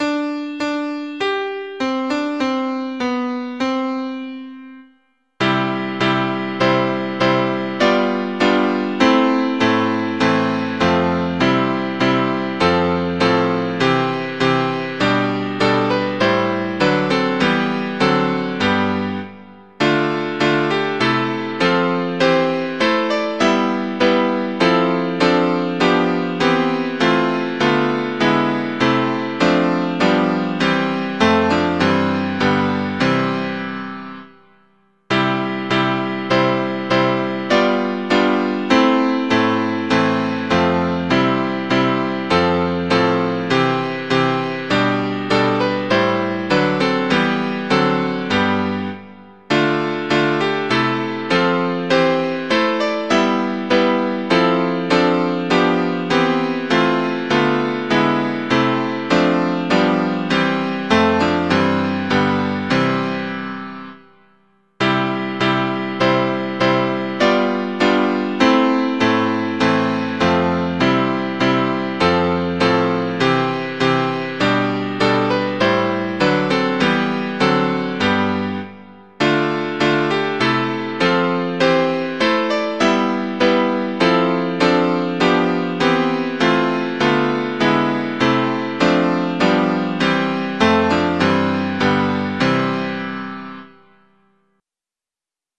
Mp3 Audio of Tune Abc source